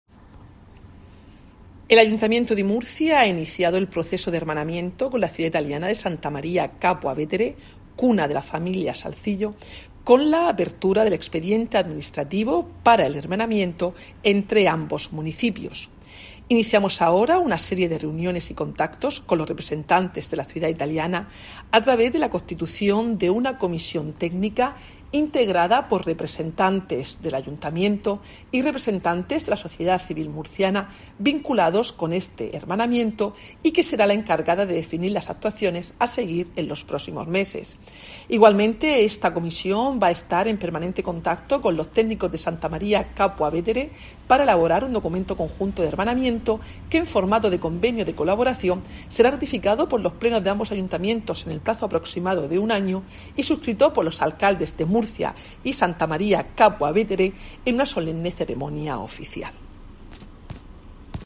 Mercedes Bernabé, concejal de Gobierno Abierto, Promoción Económica y Empleo